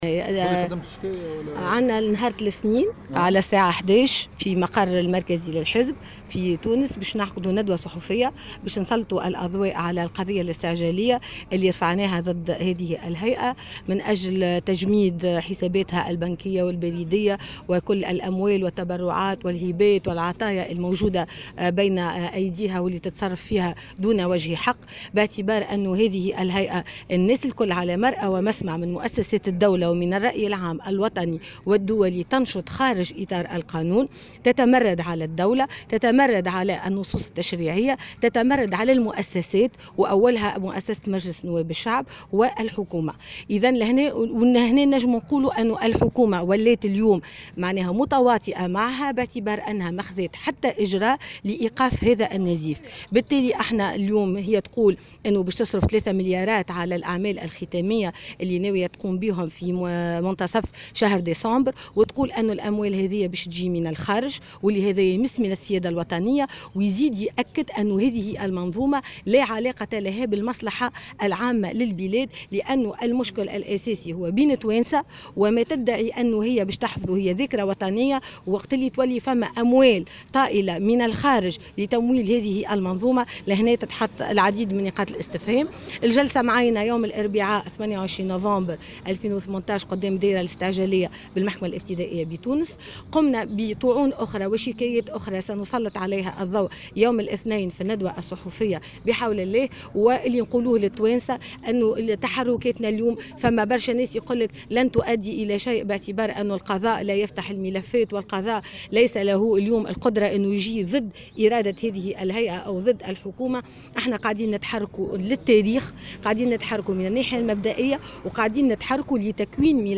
وتابعت في تصريح لمراسلة "الجوهرة أف أم" على هامش اجتماع لحزبها في المنستير، أنه تم تعيين الجلسة ليوم الأربعاء 28 نوفمبر 2018 أمام الدائرة الاستعجالية بالمحكمة الابتدائية بتونس.